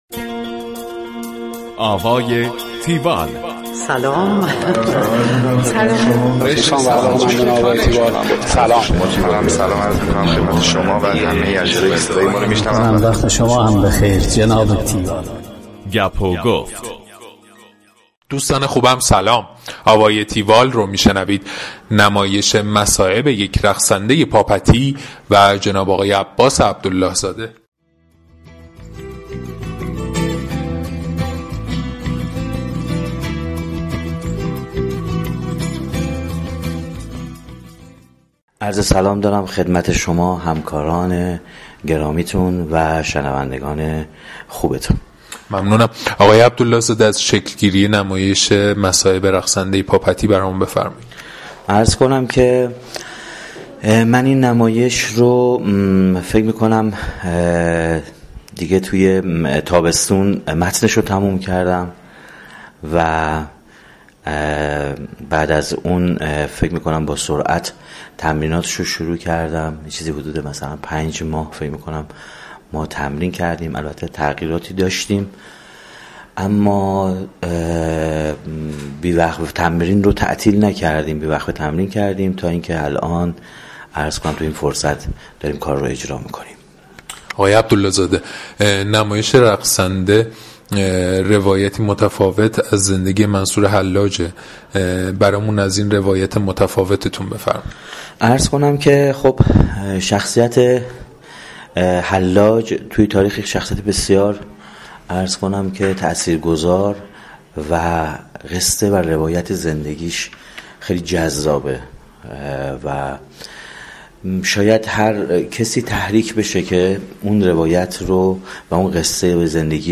گفتگو کننده